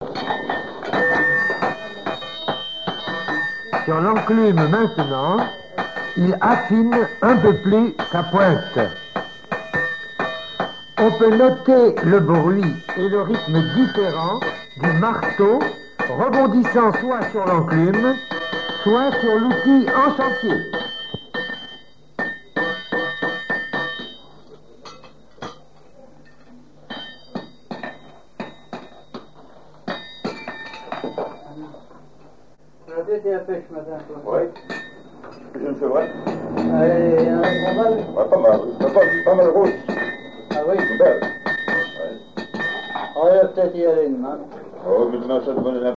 Reportage Folklore vivant
forgeron, forge
Île-d'Yeu (L')